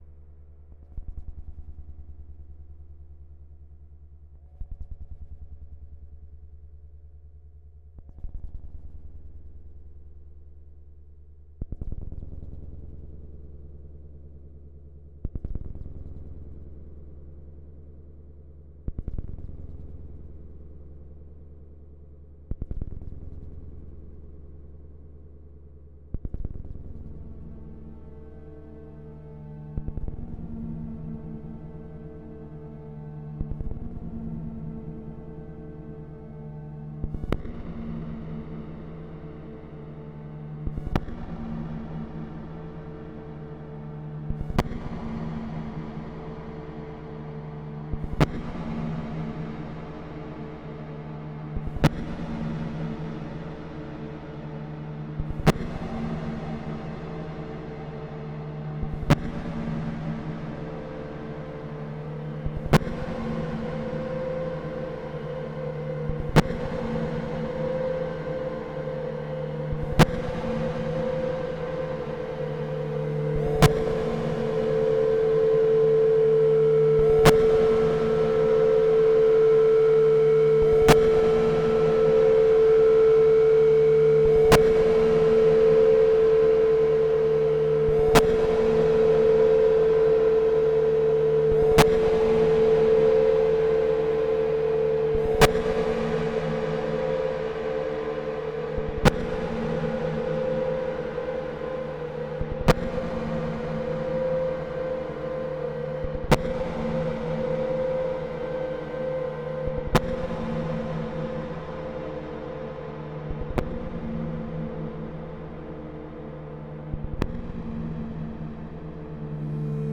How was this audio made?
My kids are grown and don’t live with me any more, so in contrast to my previous careful tuning, I just turned all the knobs at random (Mod and Sharp too) and then recorded.